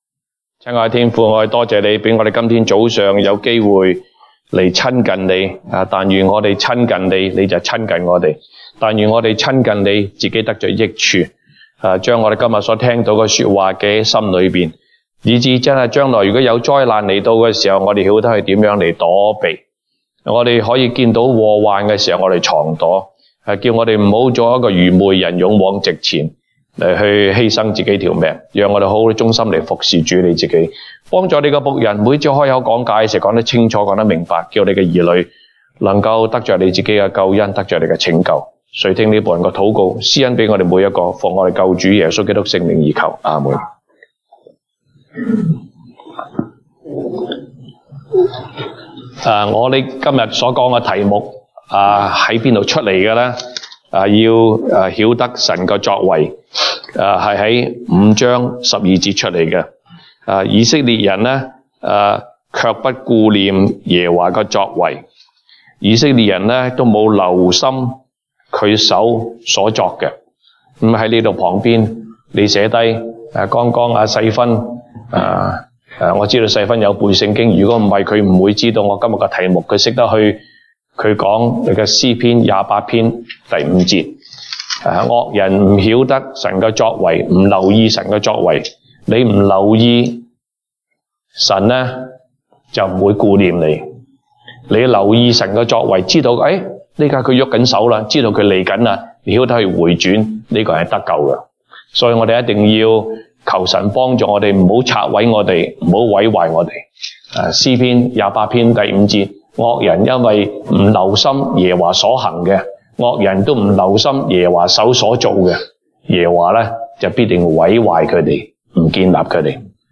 東北堂證道 (粵語) North Side: 要曉得神的作為